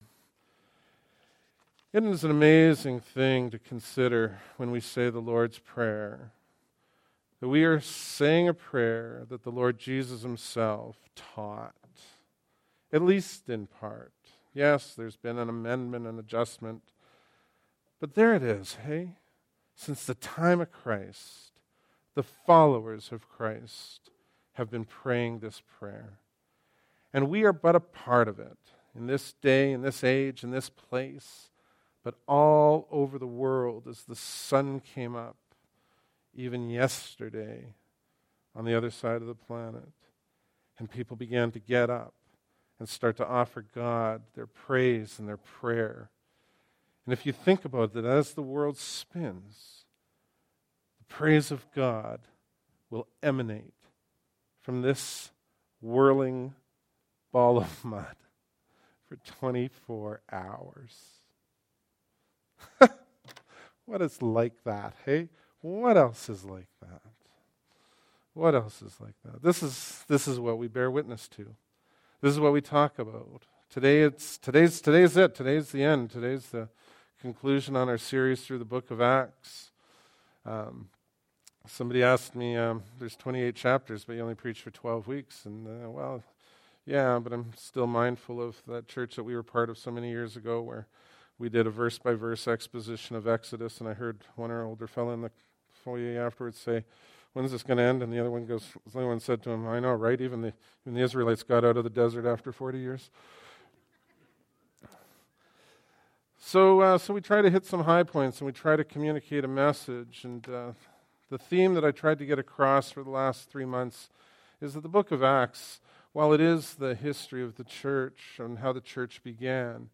Bible Text: Acts 28:17-31 | Preacher